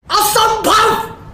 asambhav carry minati Meme Sound Effect